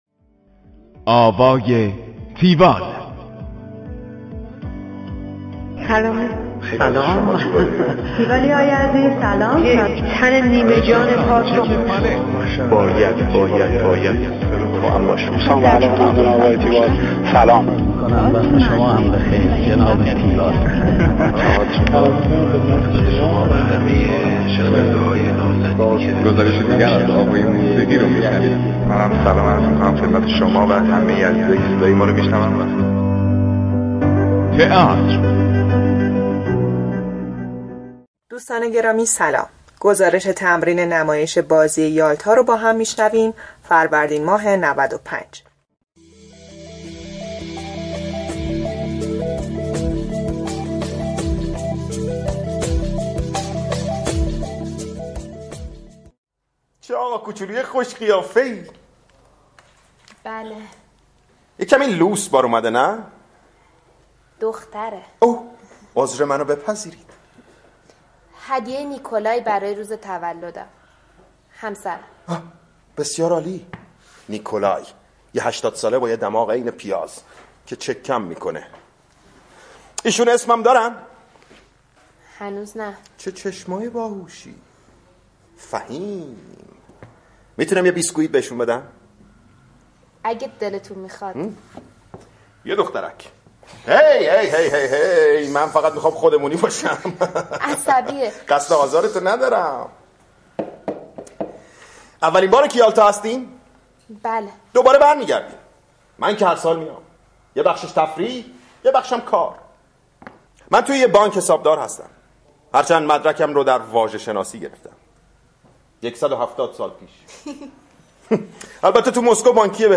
گزارش آوای تیوال از نمایش بازی یالتا